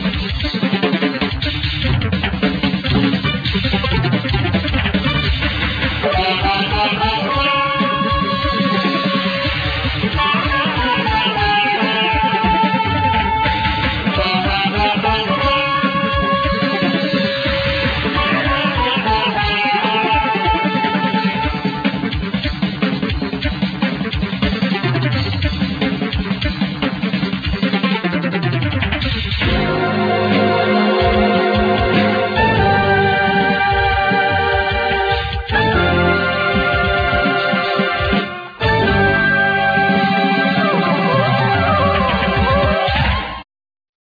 Keyboards,Vocals
Sitar,Guitar
Bass giatr,Computer
Saxophone
Clarinet
Tabla,Darabukka
Drums,Percussions,Timbales,Xylophone,Gosha nagara